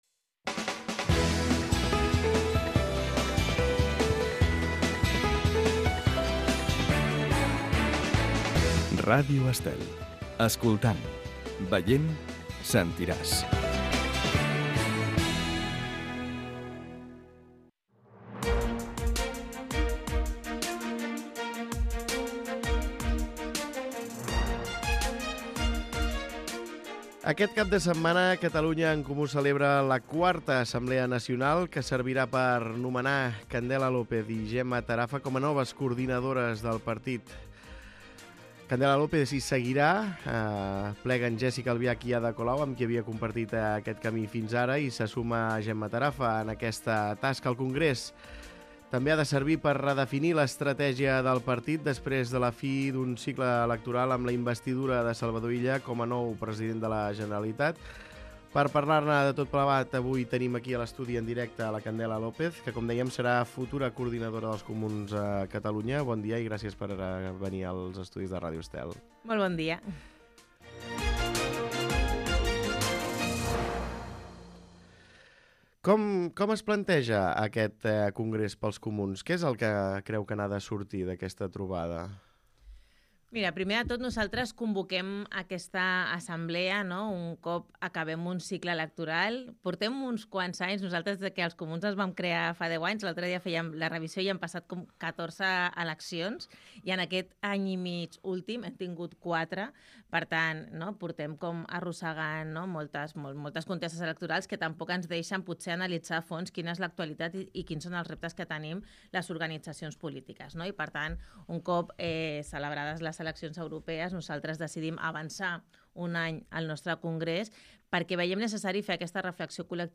Escolta l'entrevista a Candela López, coordinadora dels Comuns